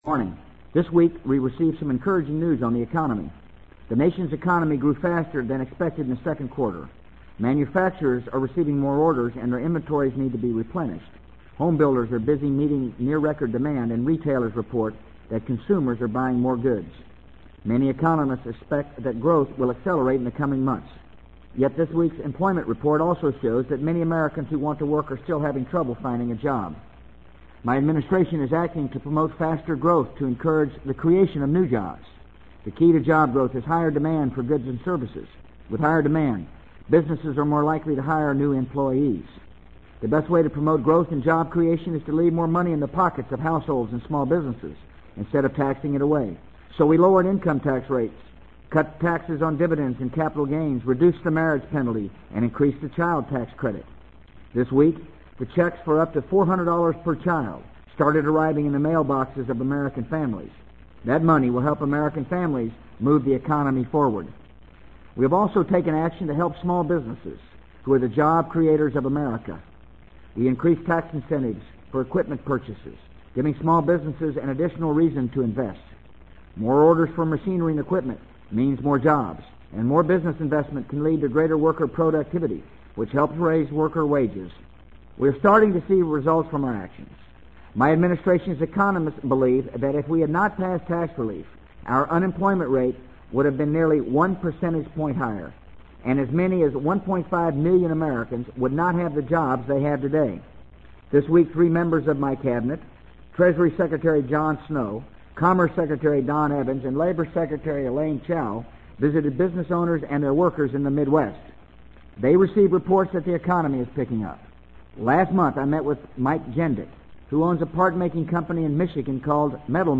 【美国总统George W. Bush电台演讲】2003-08-02 听力文件下载—在线英语听力室